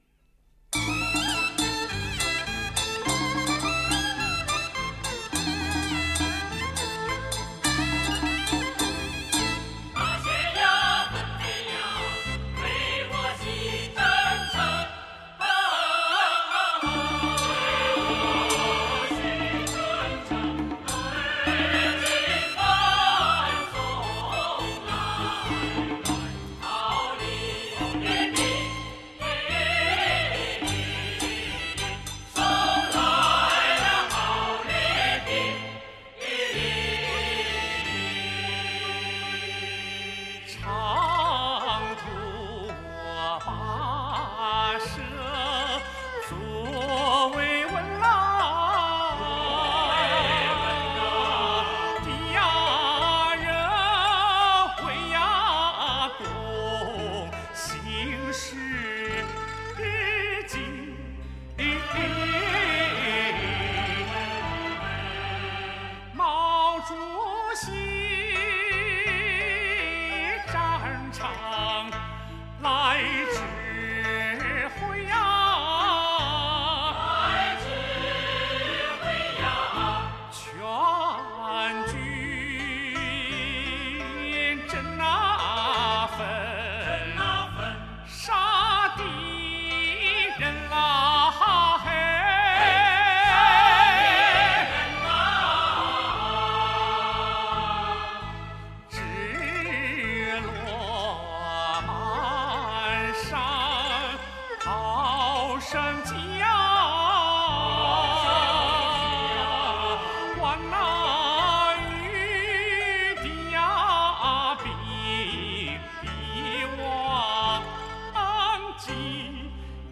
领唱